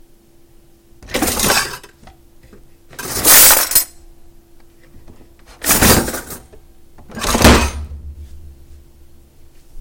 打开和关上抽屉
描述：大声打开和关闭厨房里的银器抽屉。
Tag: 打开 关闭 关闭 抽屉 大声的 木制的